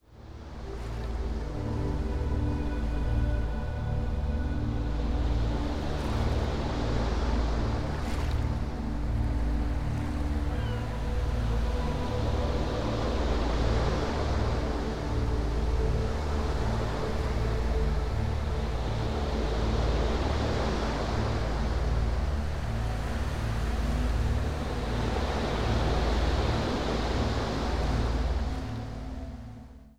Silent Subliminal nur mit Musik